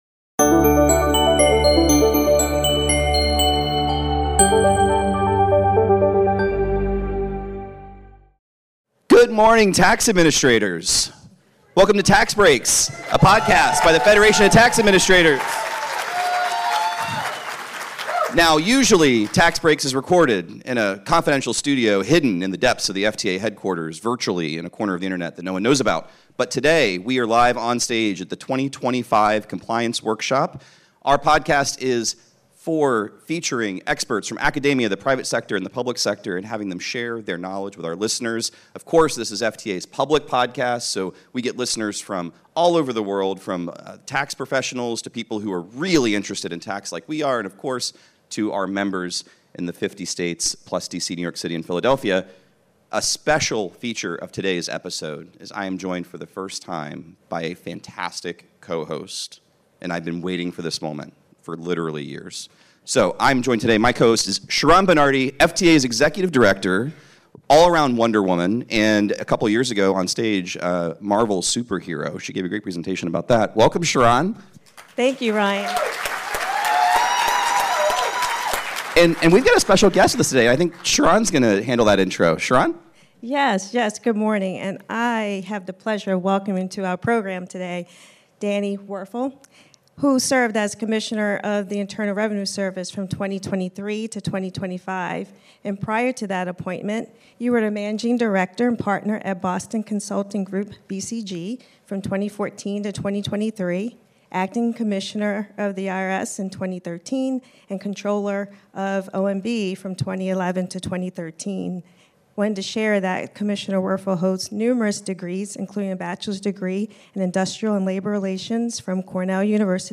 Get ready for one of our most energizing Tax Breaks episodes yet—recorded live and unfiltered in front of a room full of state and local tax administrators!